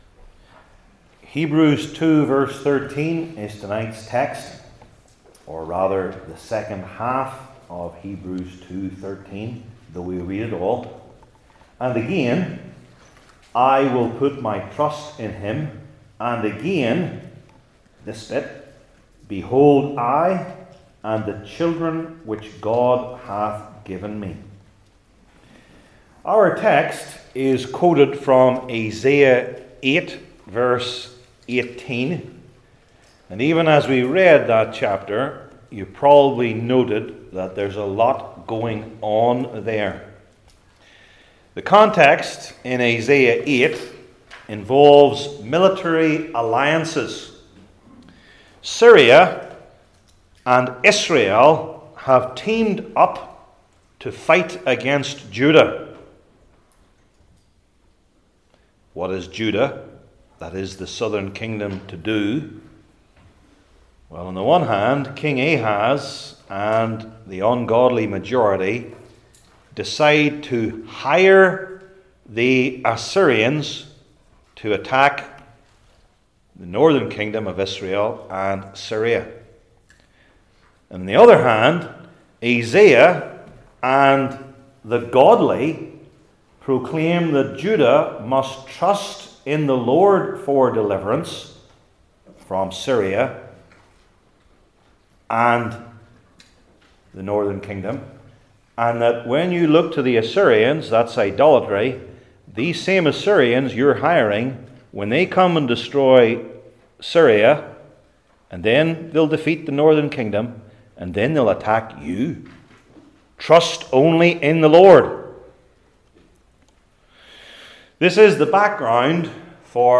Hebrews 2:13 Service Type: New Testament Sermon Series I. The Shared Humanity II.